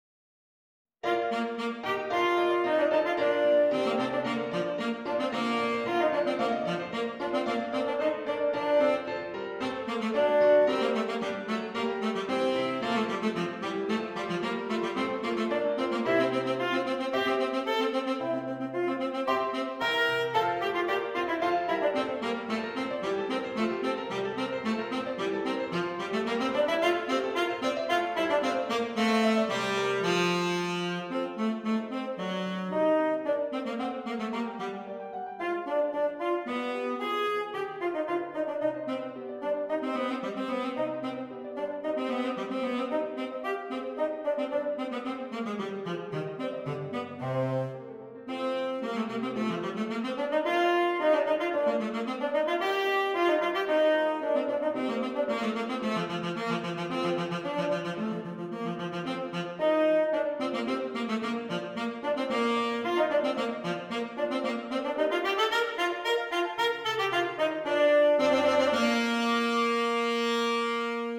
Tenor Saxophone and Keyboard